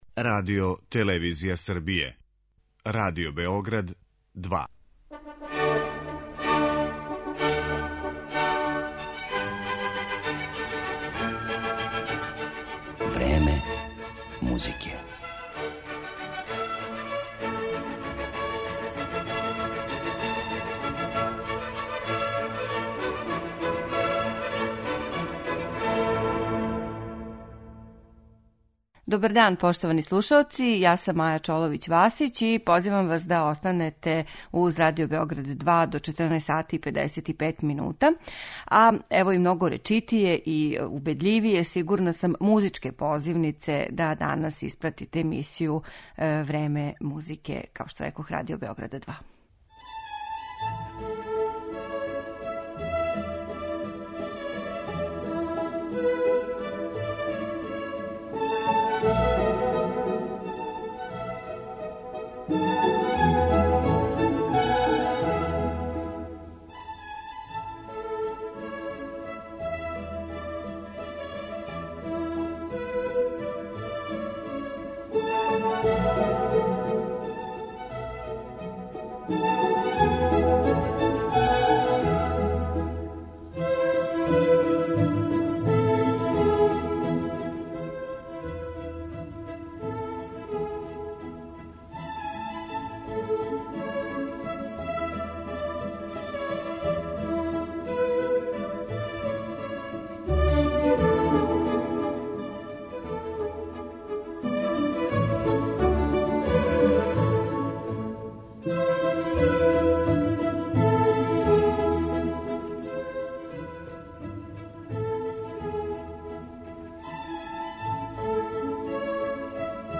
Фрагменти из Бокеринијевих дела